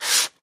in_sandpaper_stroke_01_hpx
Wood being sanded by hand. Tools, Hand Wood, Sanding Carpentry, Build